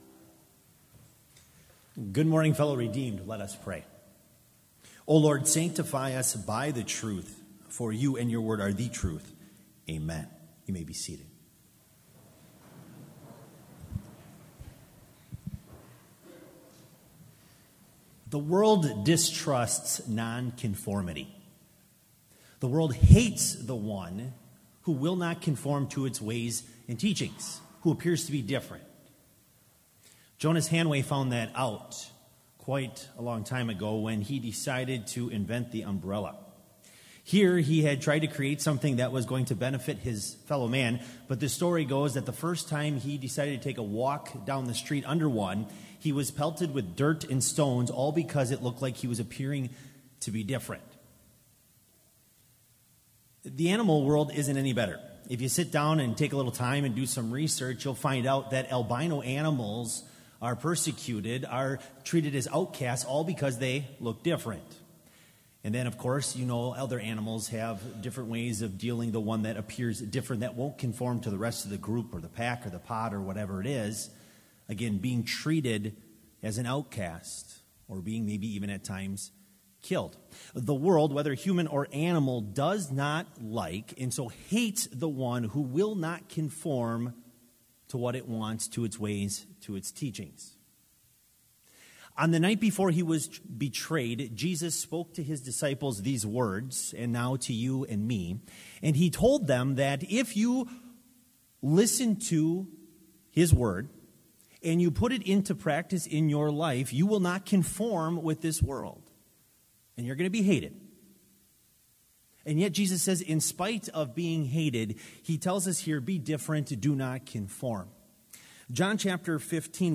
Complete service audio for Chapel - February 15, 2019
Prelude Hymn 211 - Built On the Rock the Church Doth Stand